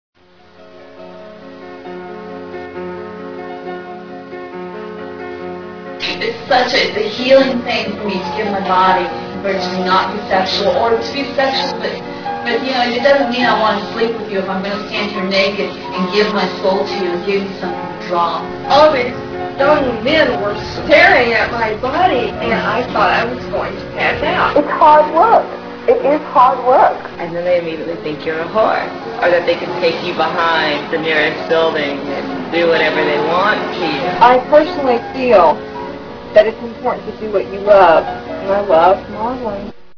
The trailer consists of some of the music and voices and stills from the video and will give you a flavor for the video.